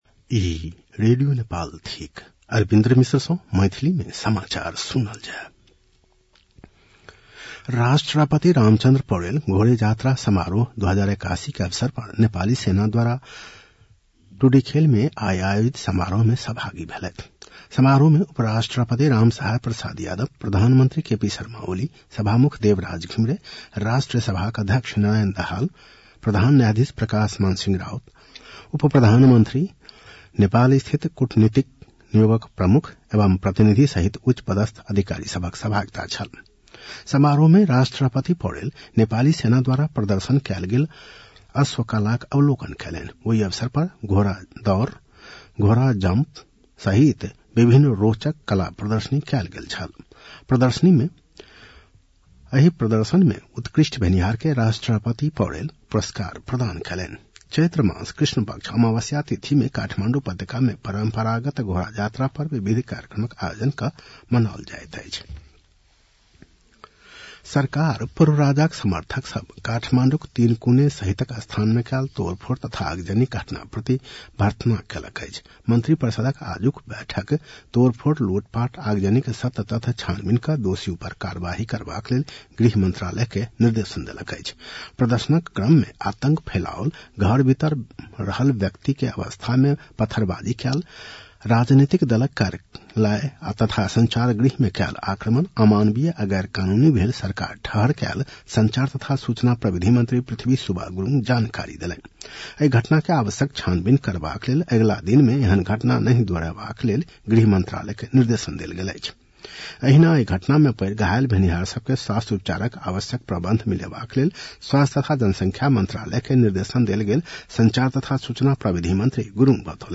मैथिली भाषामा समाचार : १६ चैत , २०८१